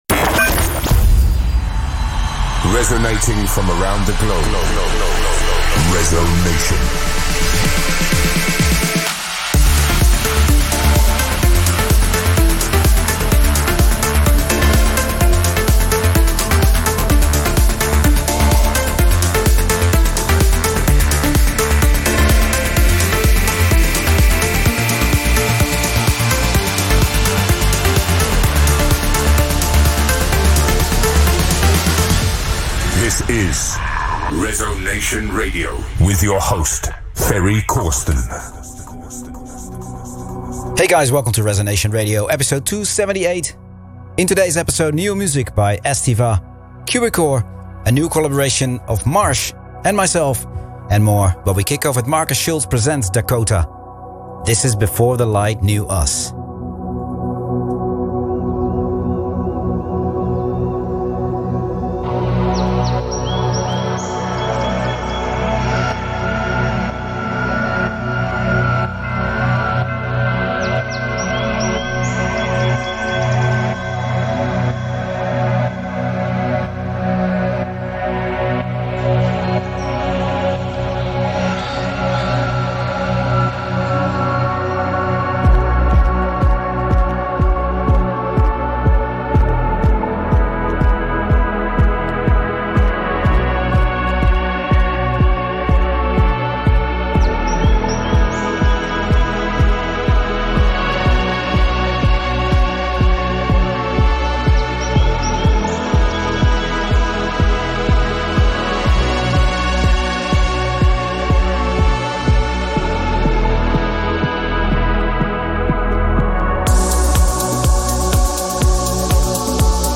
deep, melodic sounds